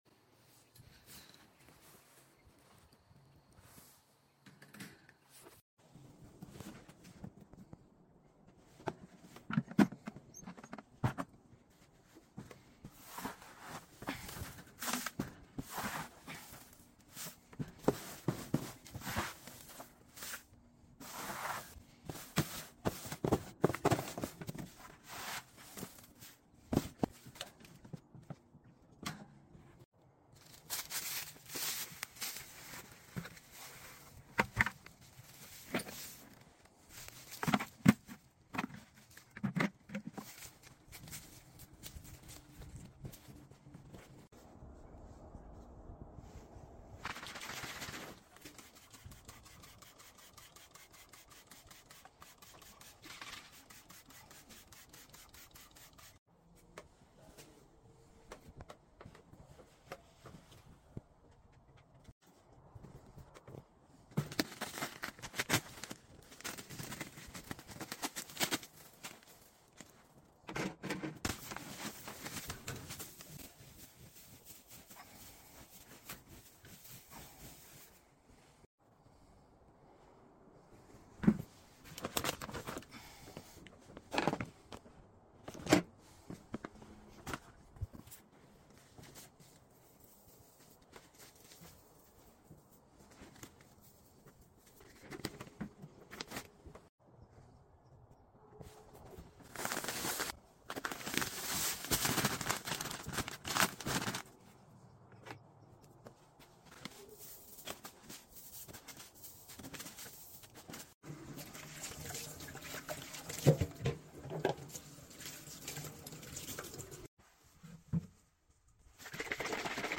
Satisfying little quail coop cleaning sound effects free download
Some ASMR!